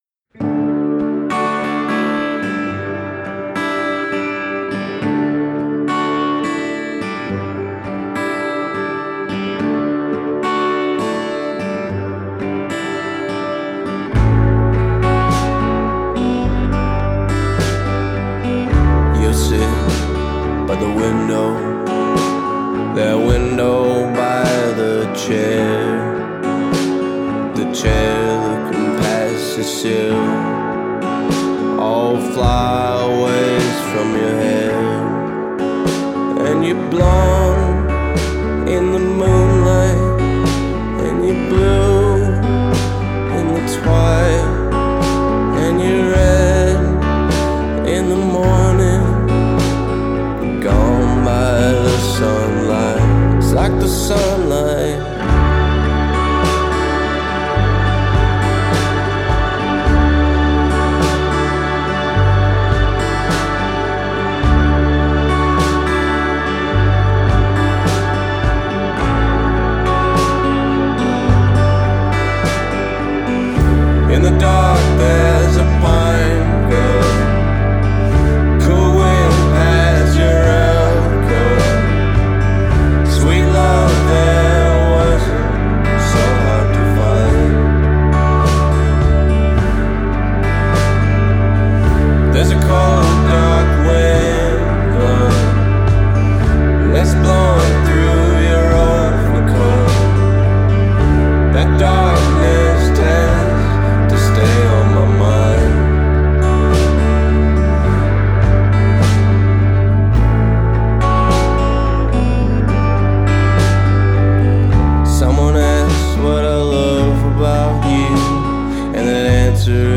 Western Canadian indie music mix